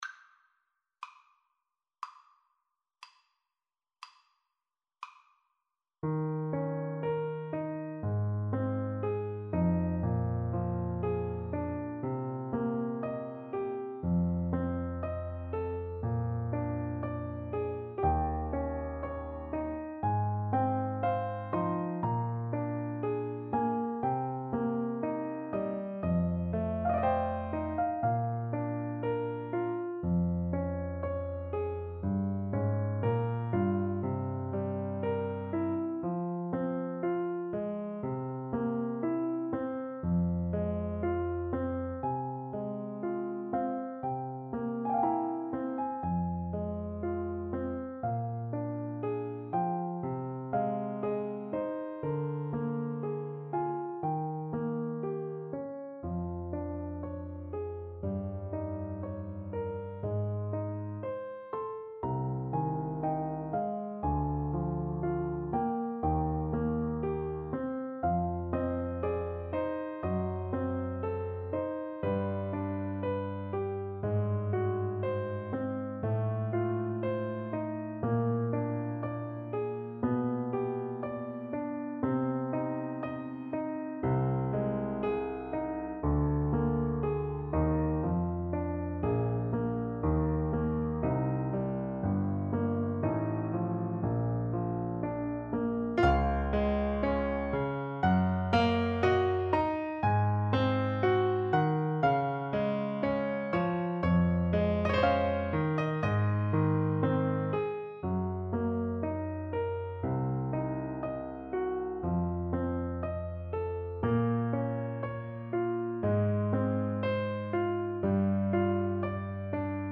~ = 60 Largo
Classical (View more Classical Trombone Music)